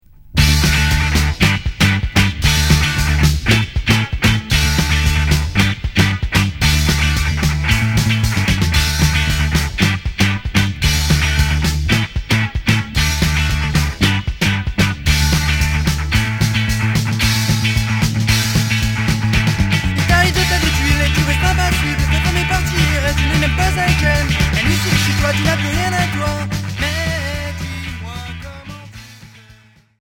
Rock Mod Unique 45t retour à l'accueil